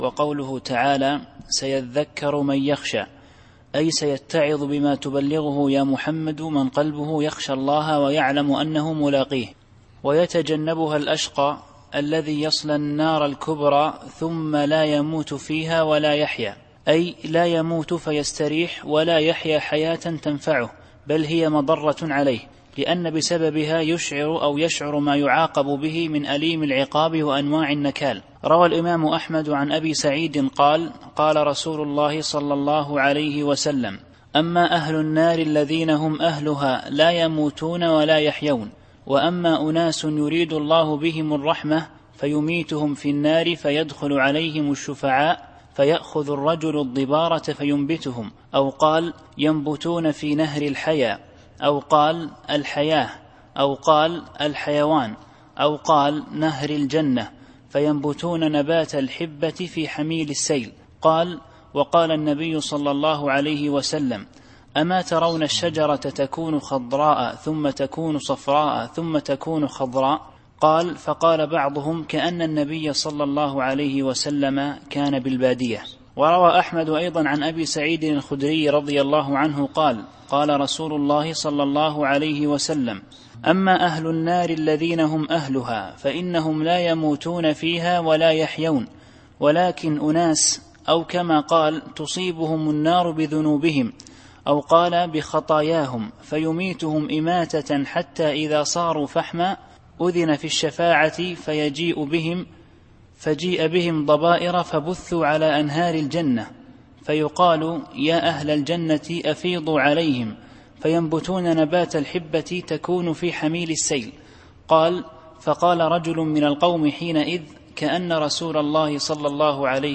التفسير الصوتي [الأعلى / 10]